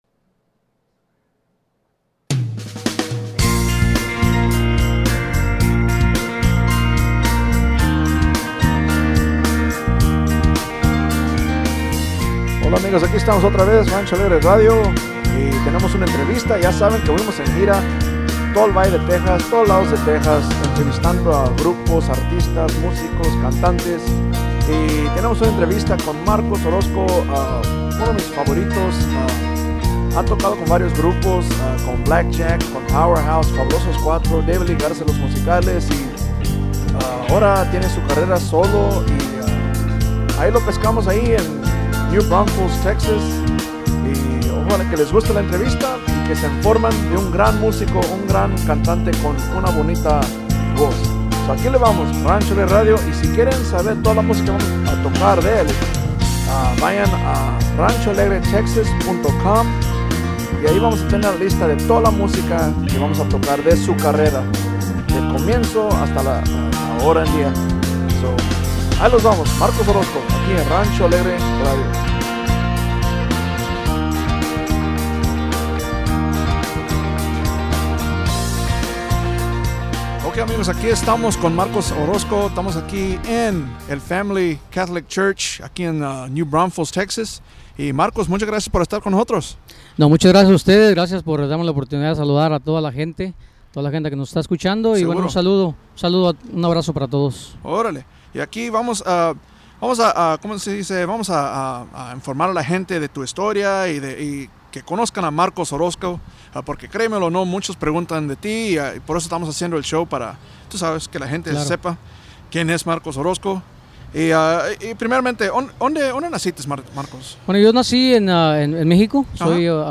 Rancho Alegre Interview